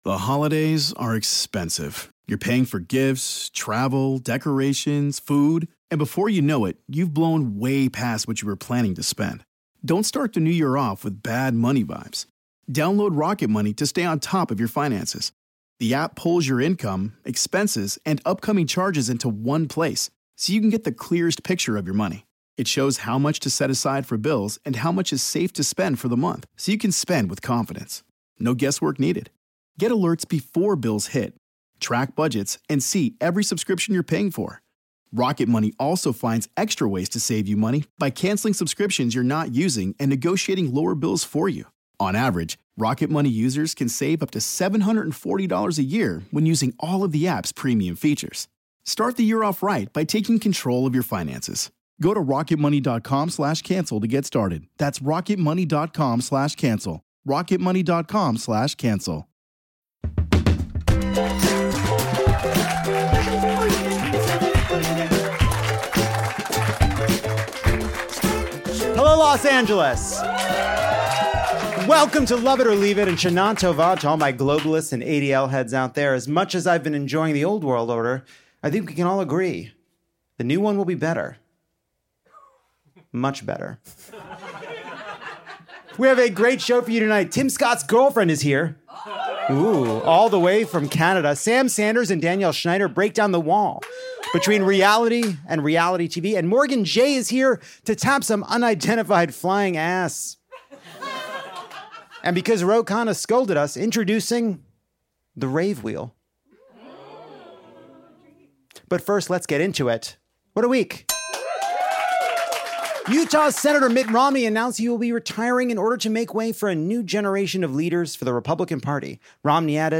Lovett Or Leave It asks “why so Sirius?” as we welcome a whole new cast of jokers to our stage.